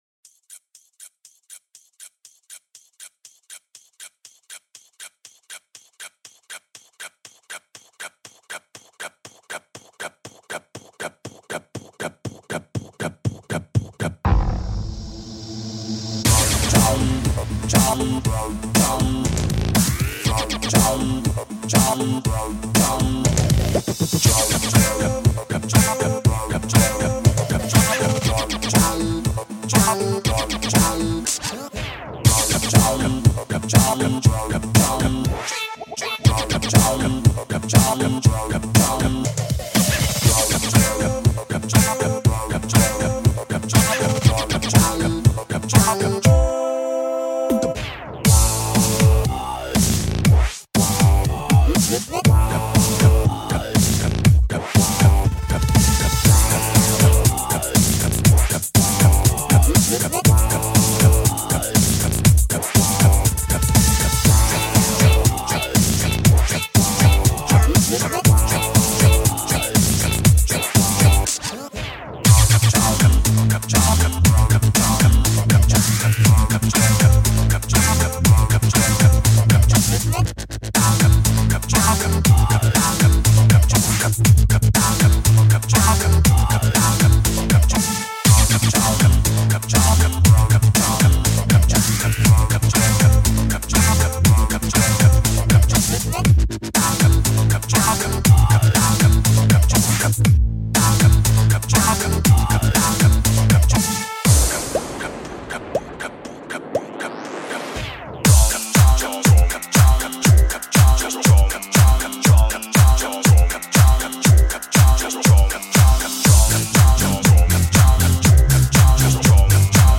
*** READ INFO *** My remix of this funny song.